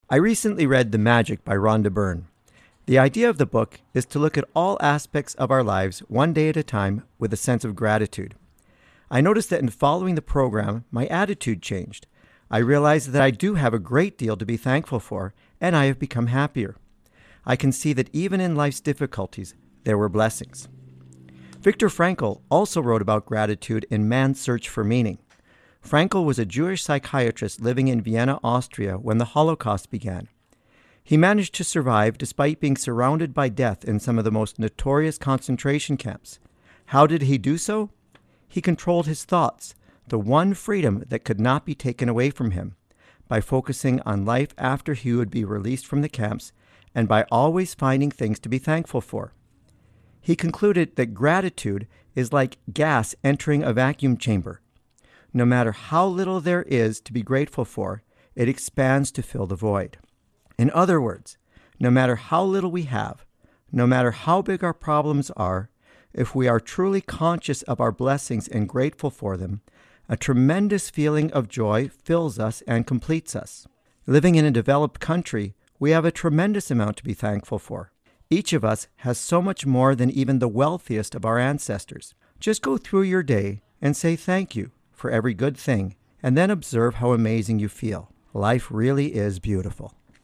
Recording Location: CFIS-FM, Prince George
Type: Commentary